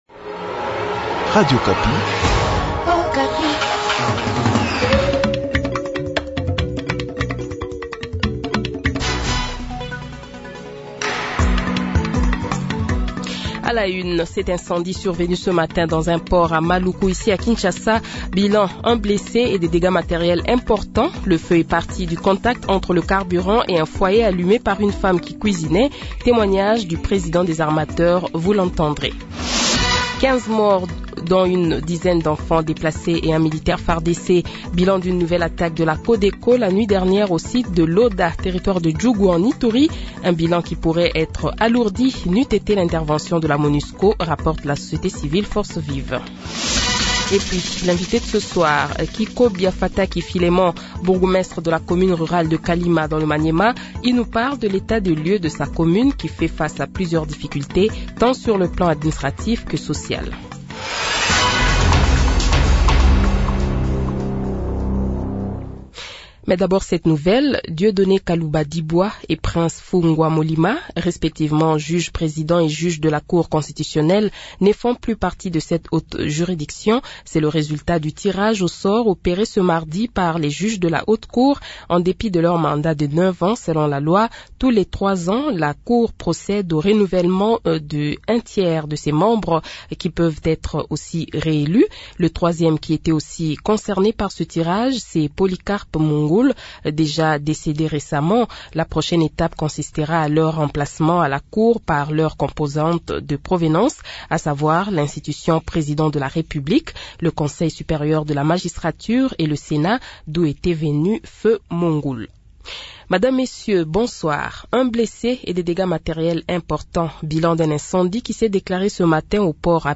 Le Journal de 18h, 10 Mai 2022 :